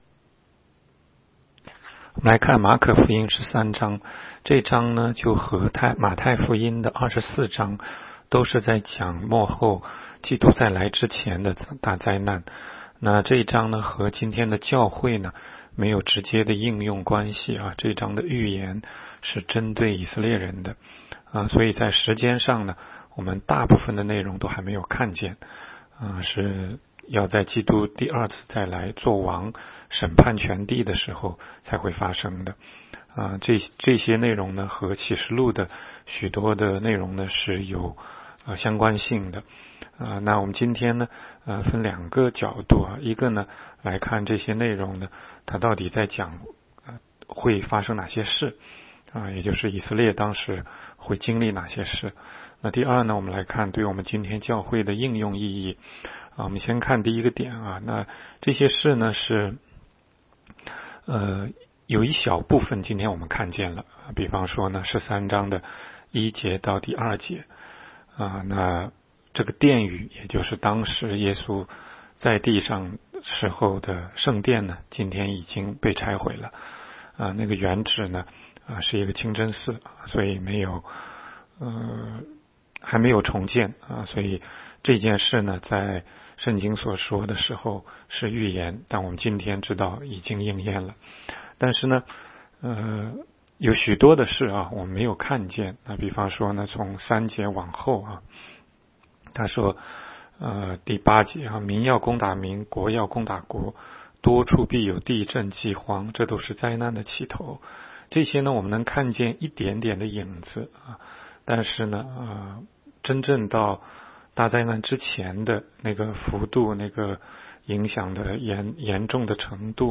16街讲道录音 - 每日读经-《马可福音》13章